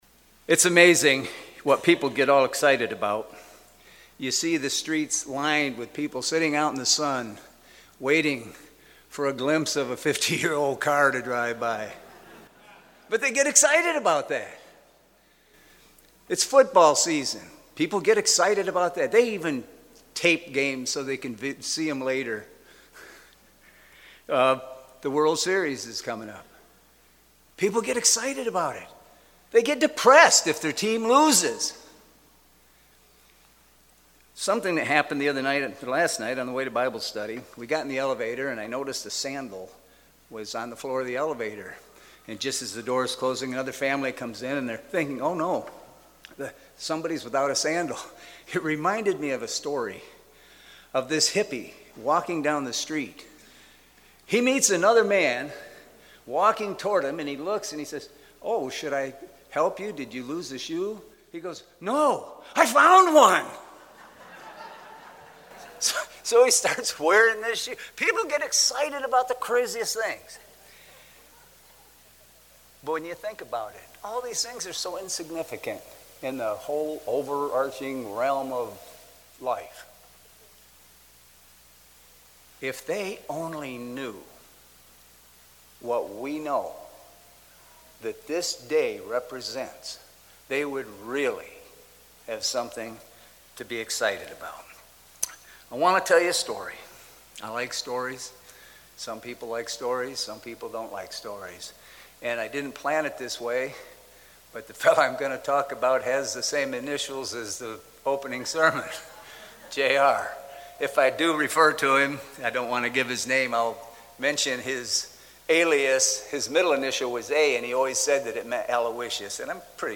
This sermon was given at the Ocean City, Maryland 2023 Feast site.